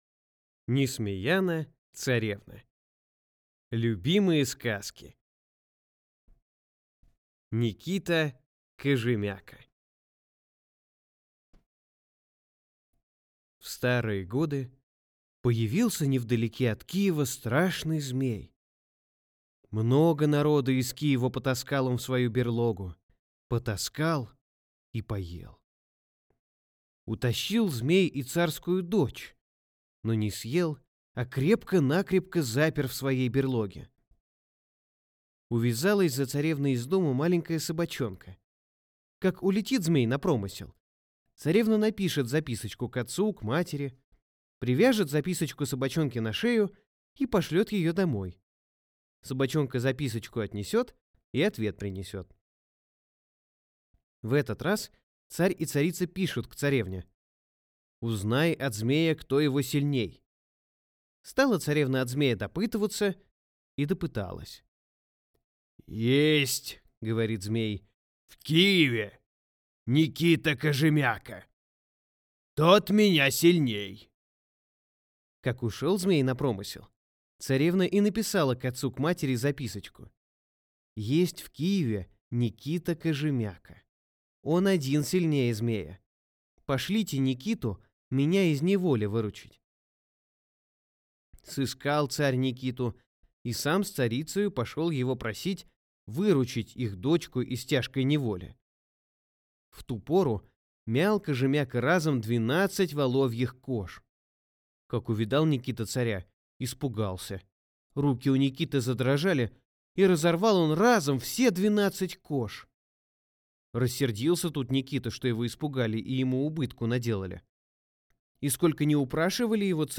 Аудиокнига Несмеяна-царевна. Любимые сказки | Библиотека аудиокниг